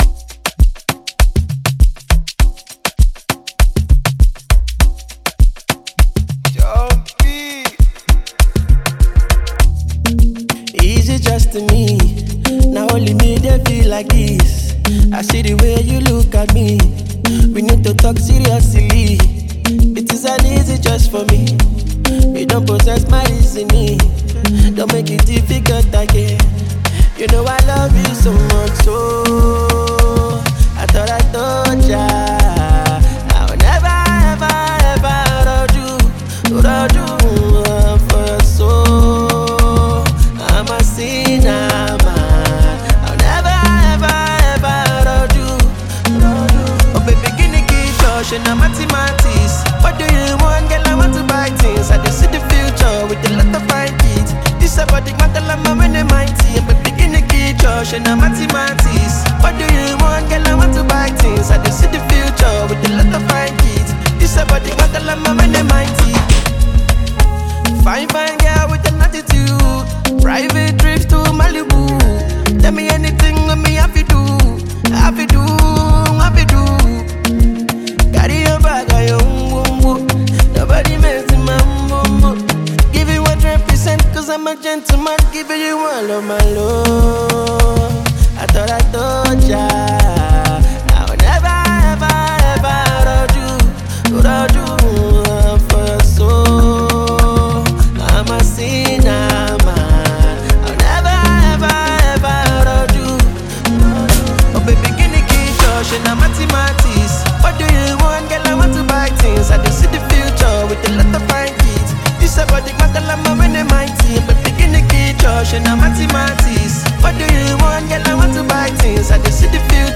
soulful love anthem